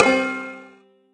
countdown_01.ogg